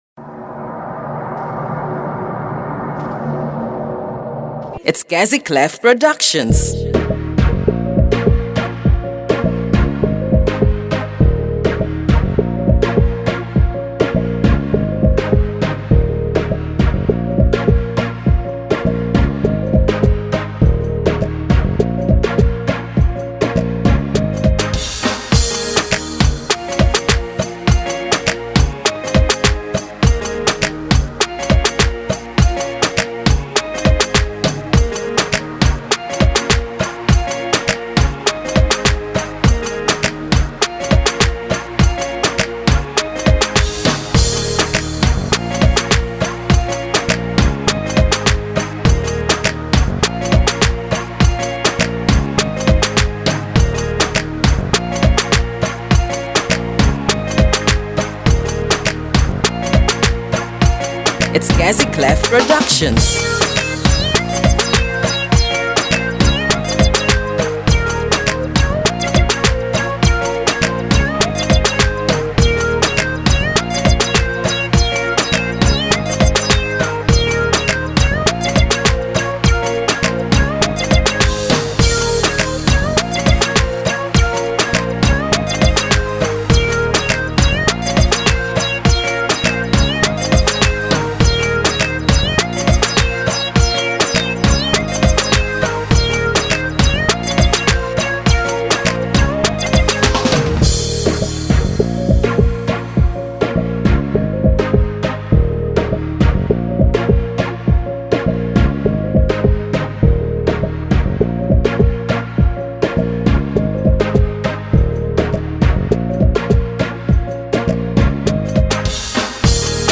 Instrumental remake